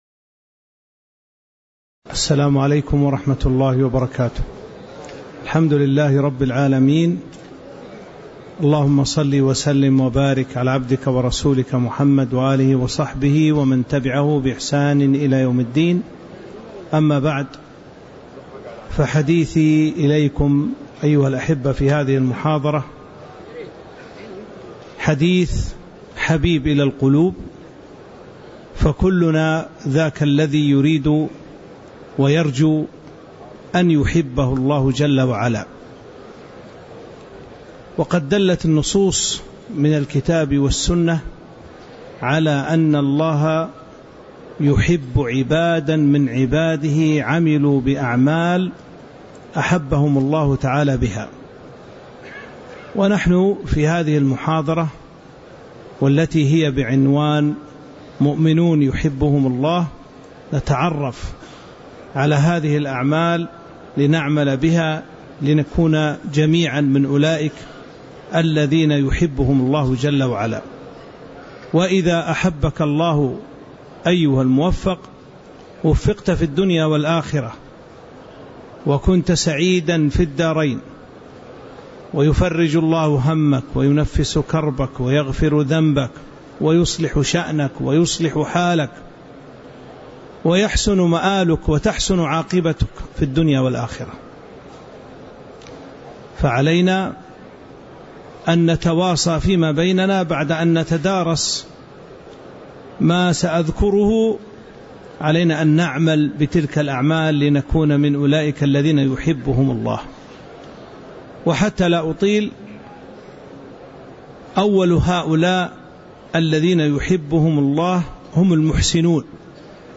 تاريخ النشر ١٧ ذو الحجة ١٤٤٥ هـ المكان: المسجد النبوي الشيخ